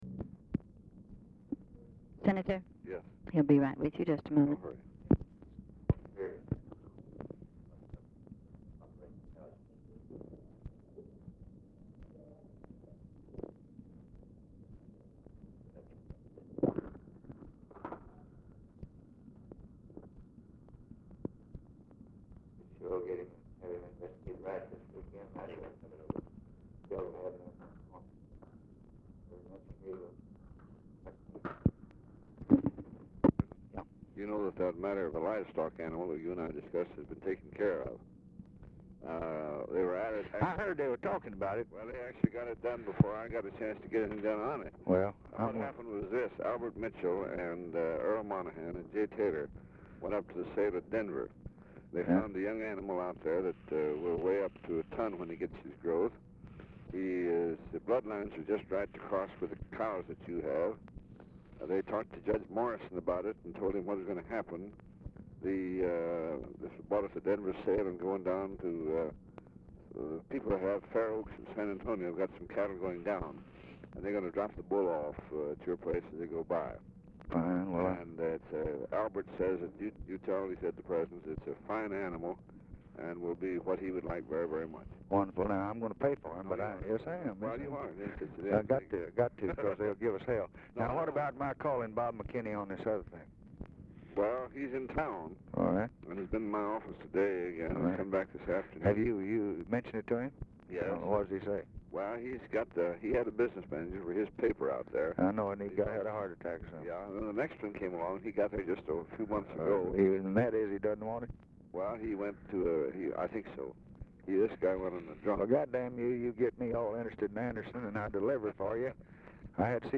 Telephone conversation # 1415, sound recording, LBJ and CLINTON ANDERSON, 1/18/1964, 11:16AM | Discover LBJ
Format Dictation belt
Location Of Speaker 1 Oval Office or unknown location